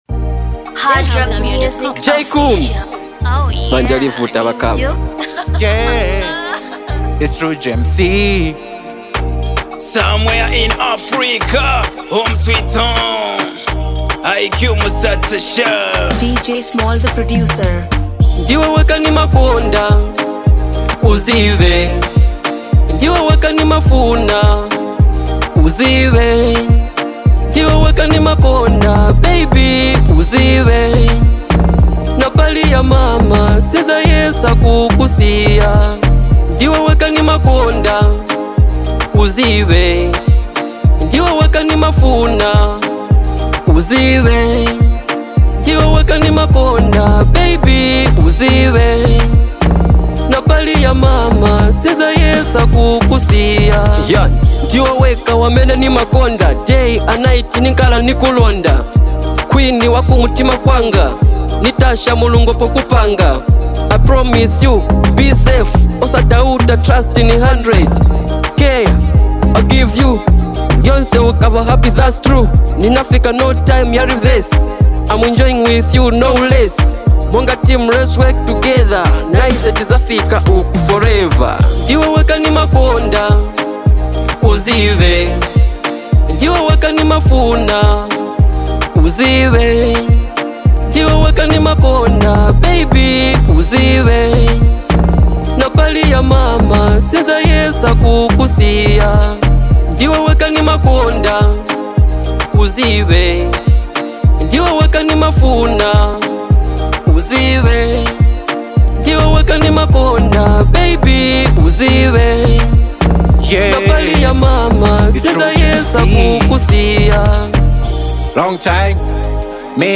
heartfelt track
blends soulful melodies with sincere lyrics